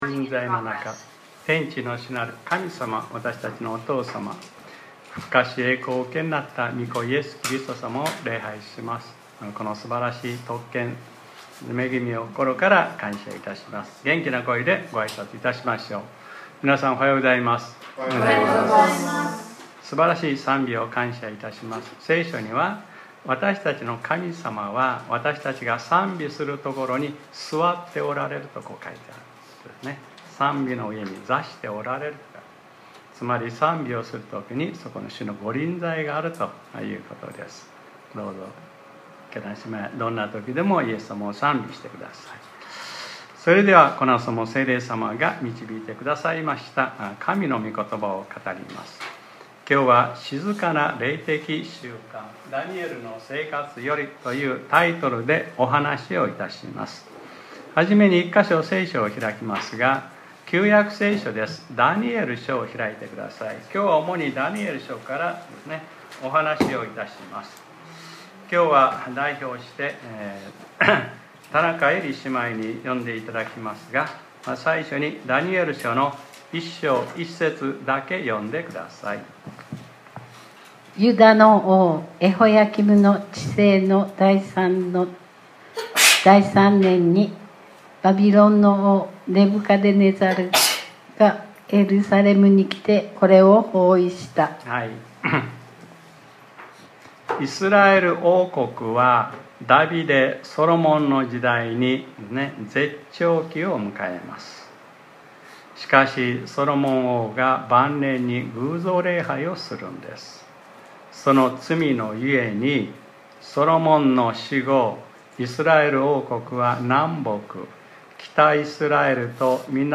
2025年09月28日（日）礼拝説教『 静まってわたしこそ神であることを知れ 』